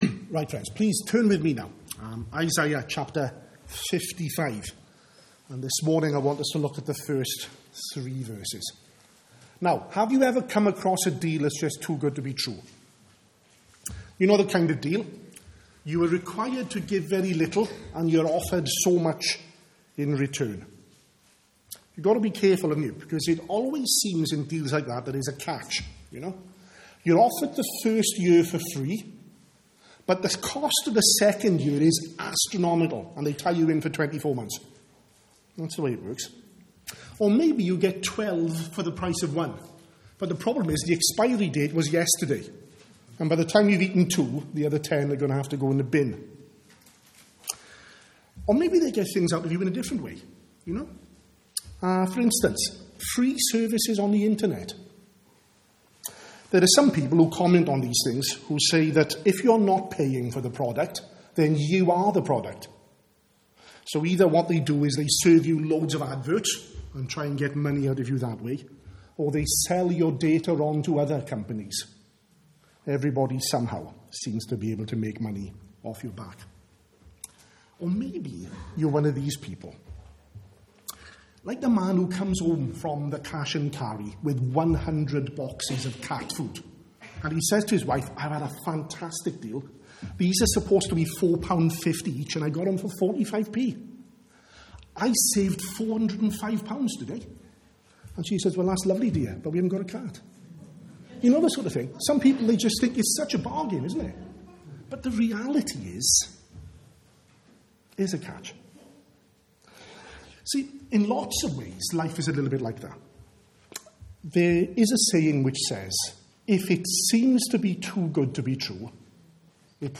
at the morning service.